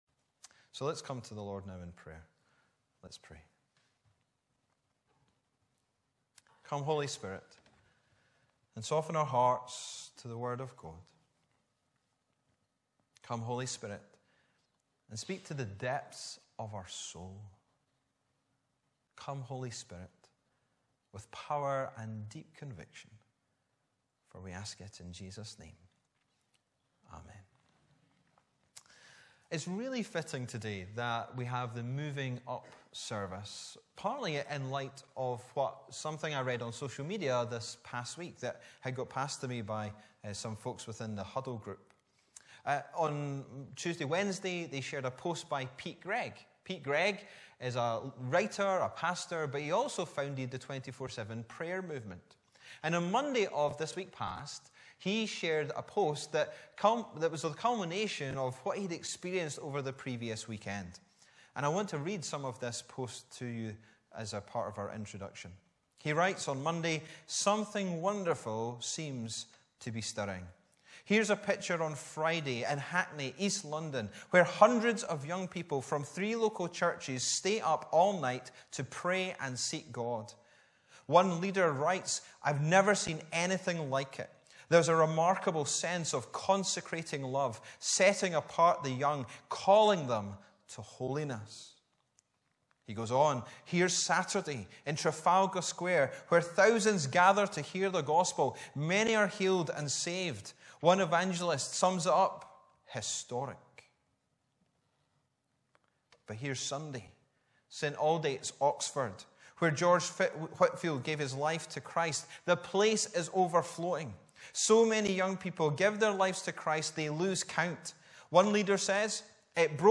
Bible references: Ecclesiastes 1:1-18 Location: Brightons Parish Church